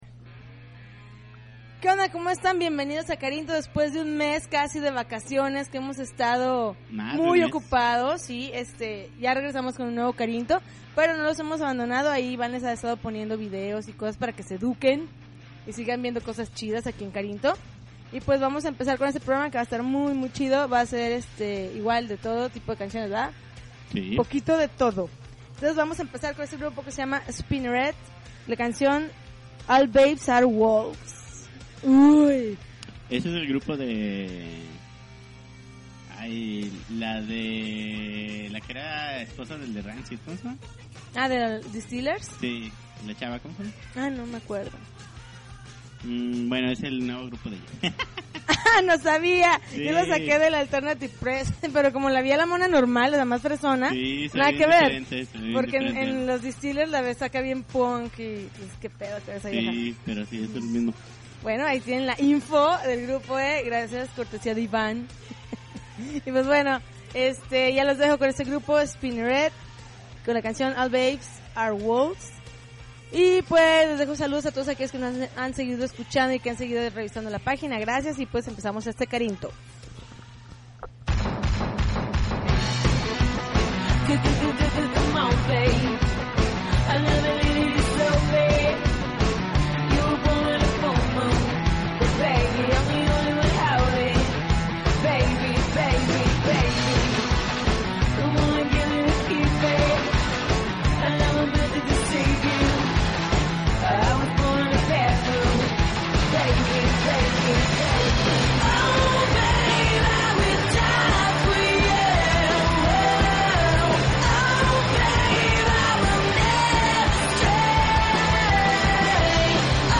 June 21, 2009Podcast, Punk Rock Alternativo